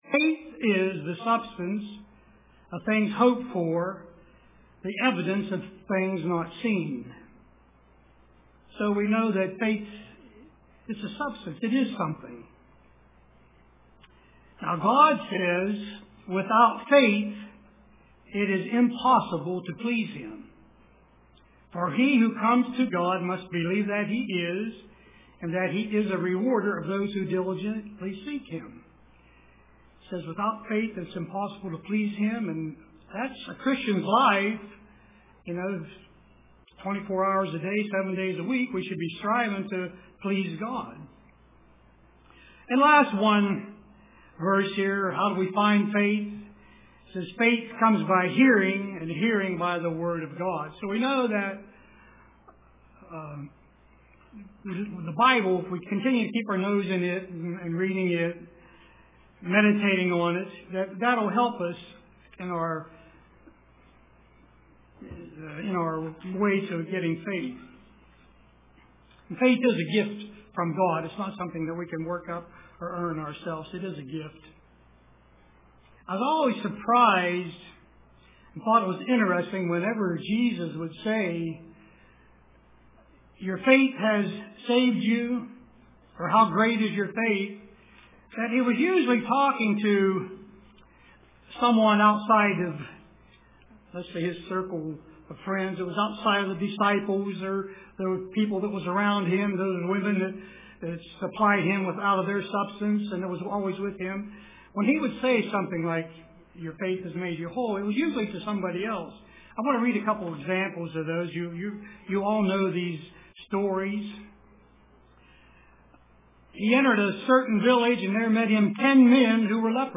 Print We walk by Faith and not by Sight UCG Sermon Studying the bible?